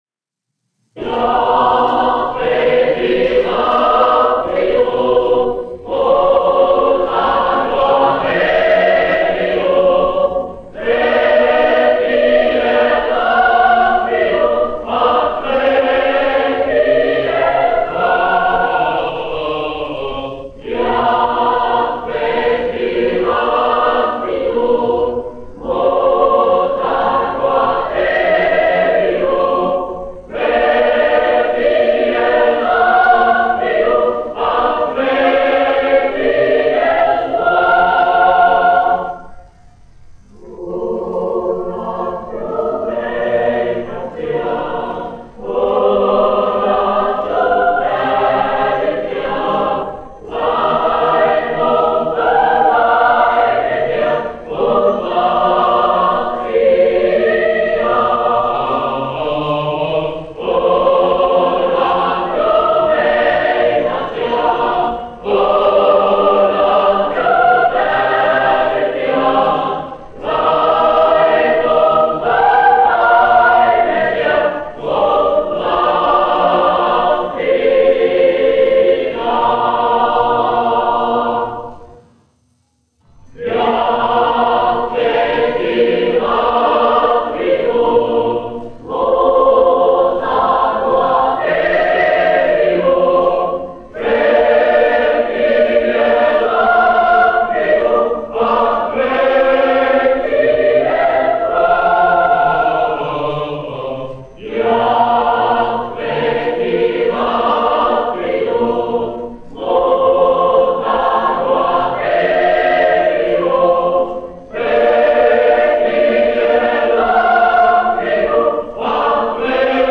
Latvijas Nacionālā opera. Koris, izpildītājs
Jozuus, Pēteris Pauls, 1873-1937, diriģents
1 skpl. : analogs, 78 apgr/min, mono ; 25 cm
Nacionālās dziesmas un himnas
Kori (jauktie)
Skaņuplate